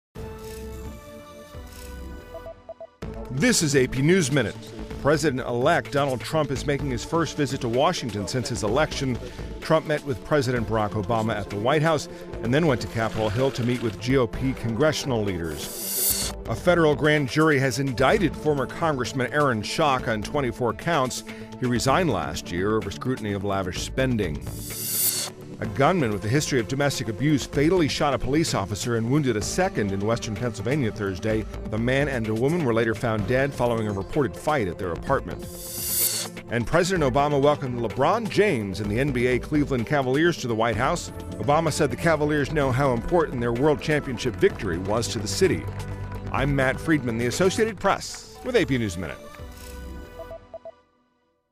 美语听力练习素材:特朗普与奥巴马总统在白宫会晤|美语听力练习素材
News